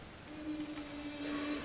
The setting for two voices (either two sopranos, or soprano and tenor) clearly indicate that the chansons were intended to be performed by the dedicatees themselves.
"Si douce est la douleur", two-part chanson from Sonets avec une chanson, contenant neuf parties l'une suivant l'autre, le tout a deux parties ... livre premier, Antwerp, Phalèse-Bellère, 1592.
De Castro's bicinia for two voices are beautiful examples of intimate domestic music. The limitations of the setting notwithstanding - two-part chords can sound rather poor: they only consist of one interval - De Castro tried to add as much variety as possible.
Also note the dissonant decelerations on these words.
The melody is richly varied, with well balanced syllabic and melismatic fragments.